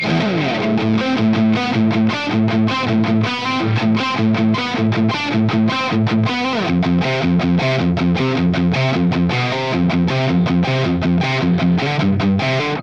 This one does it with great balance and alot of bite!
Metal Riff
RAW AUDIO CLIPS ONLY, NO POST-PROCESSING EFFECTS
Hi-Gain